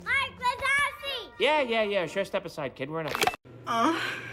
Mike-wazowski-moan-sound-effect.mp3